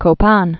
(kō-pän)